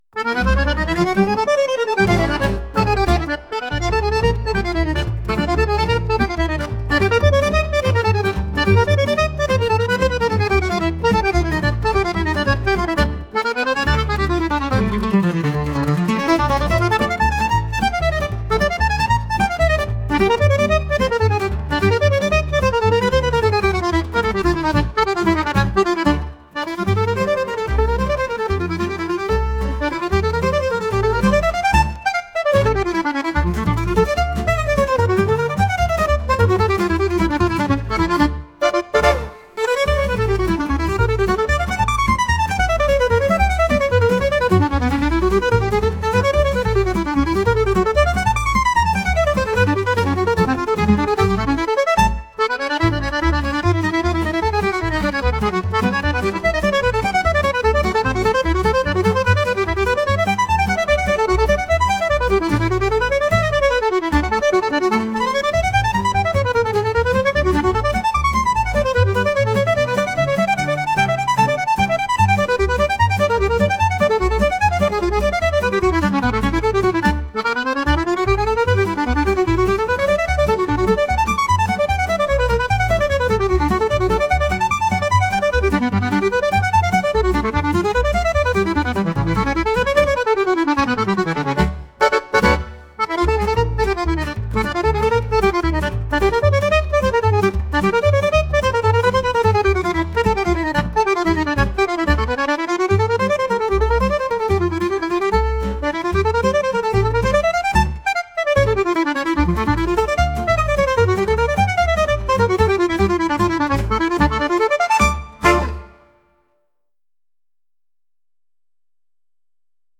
激しいダンスを踊るような速いアコーディオンの音楽です。